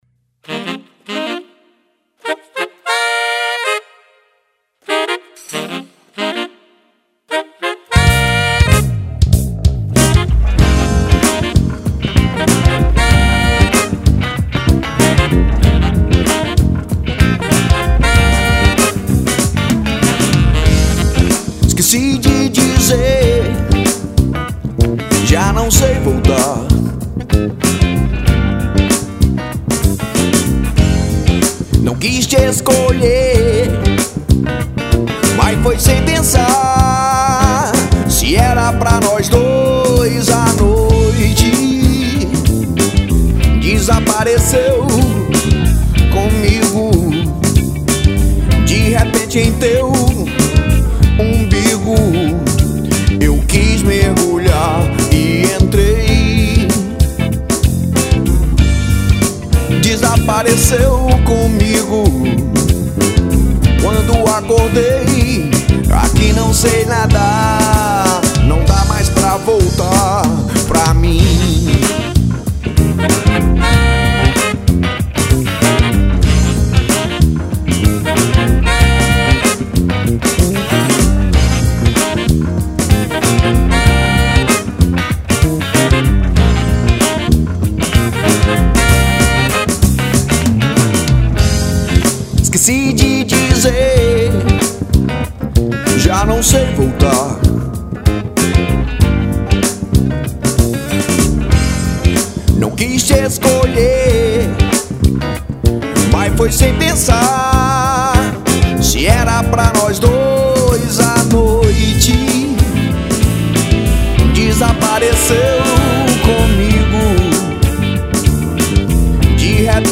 1961   03:32:00   Faixa:     Rock Nacional